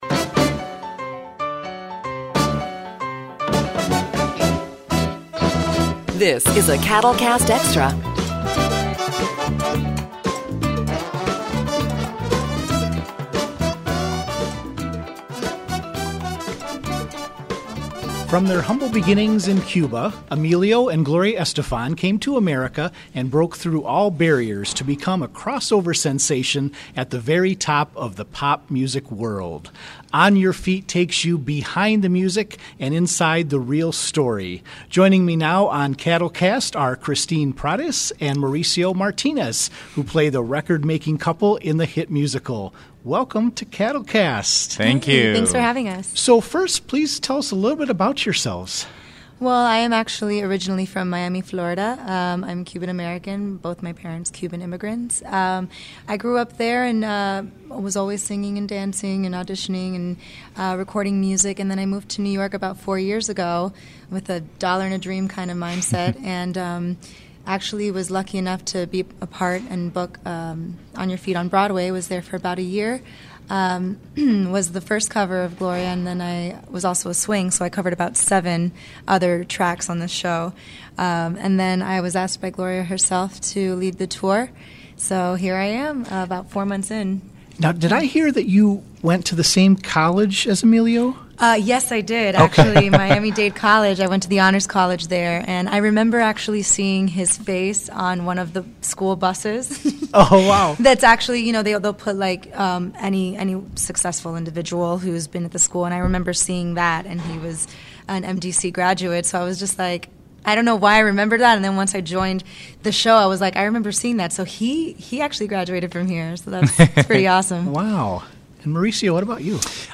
CADLcast Extra: Cast members from On Your Feet!